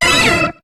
Cri d'Hypocéan dans Pokémon HOME.